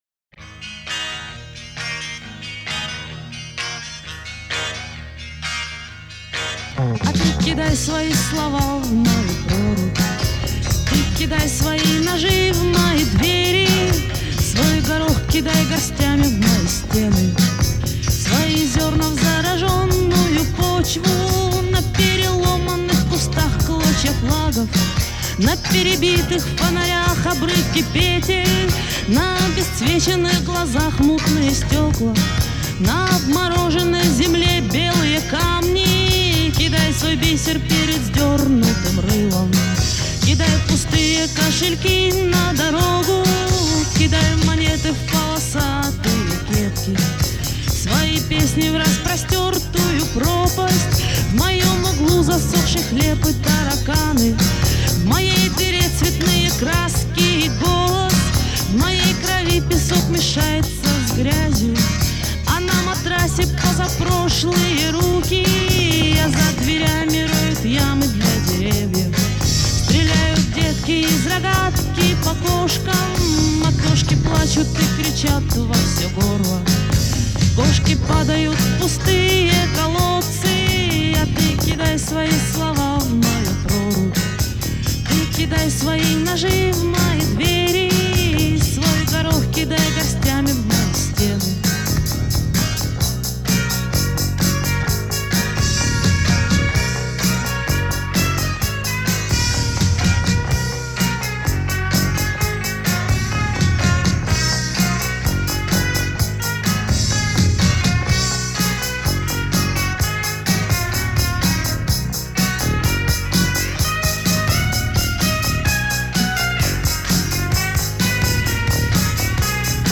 Жанр: Folk-Rock, Psychedelic Rock, Post-Punk
Забавная запись.
вокал, ритм-гитара